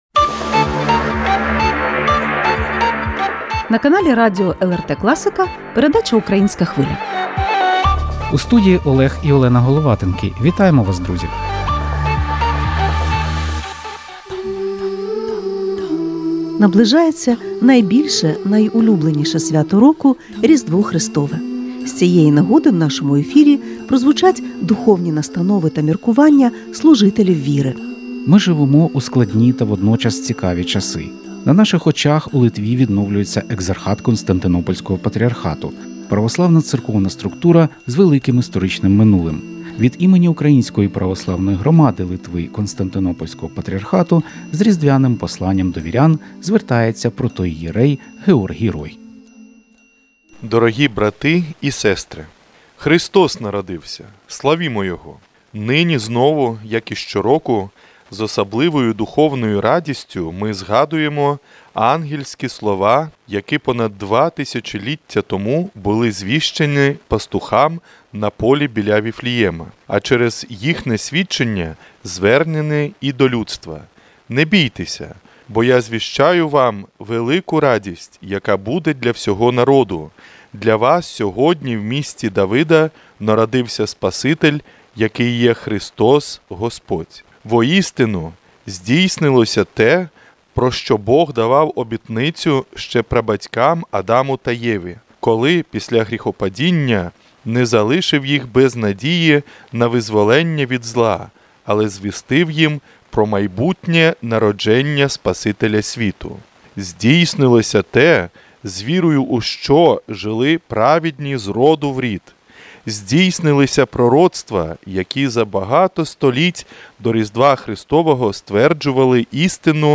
Українське Різдво в Литві: Святкові звернення, інтерв'ю зі священиком, розклад богослужінь